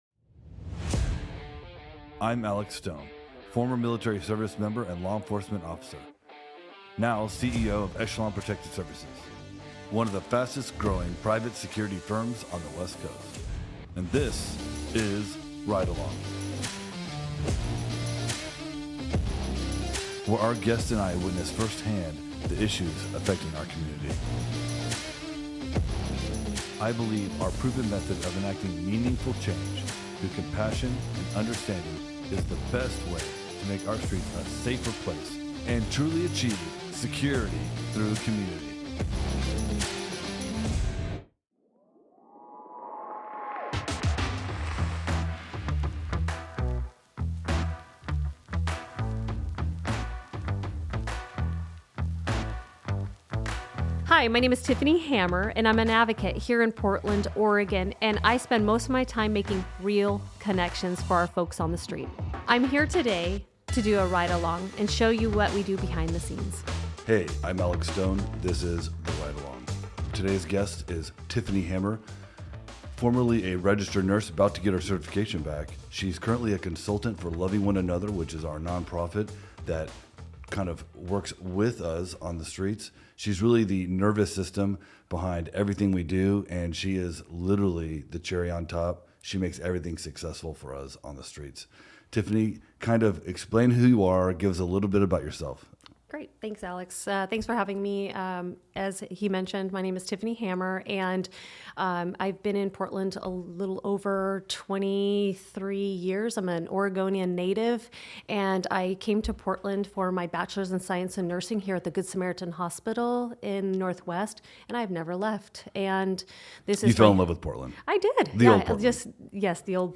Tune in for a heartfelt discussion on nursing, community wellness, and the transformative potential of love in action.